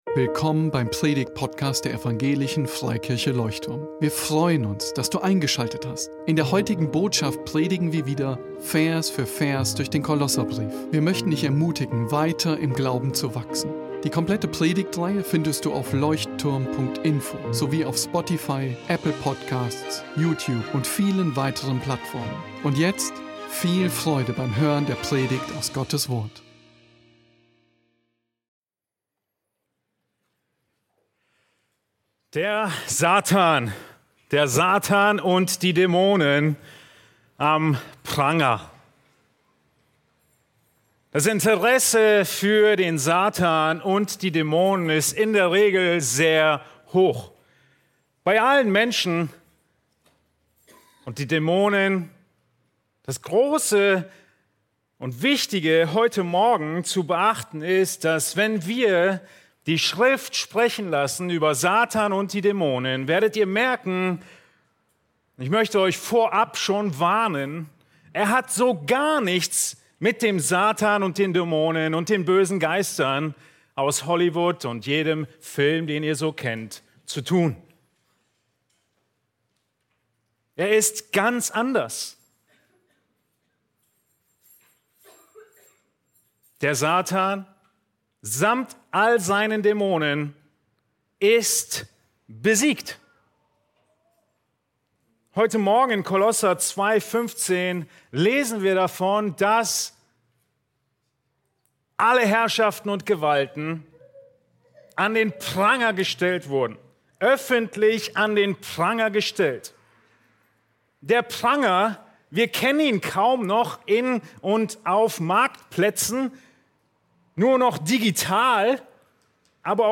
In dieser Predigt erfährst du, wie sehr sich das biblische Bild von Satan und seinen Dämonen von den Darstellungen in Hollywood unterscheidet.